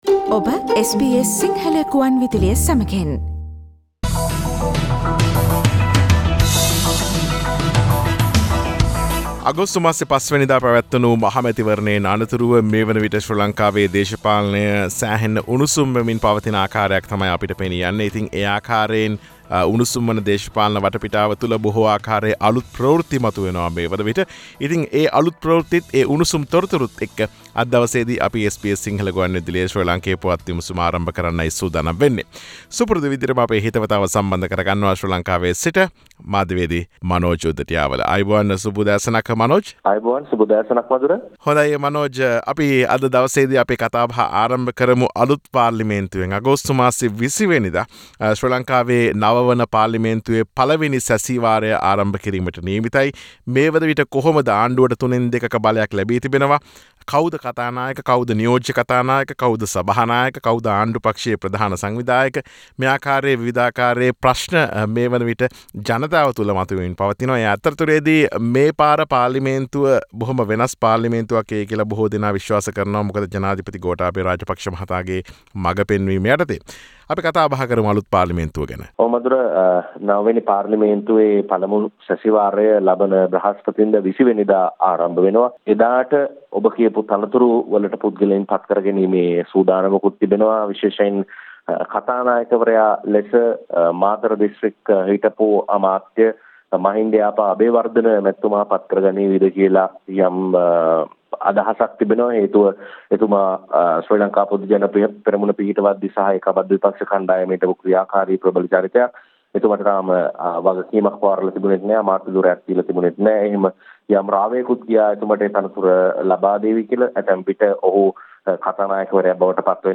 SBS Sinhala radio brings you a comprehensive wrap up of the highlighted news from Sri Lanka with Journalist – News and current affair